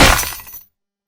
crash-sfx.mp3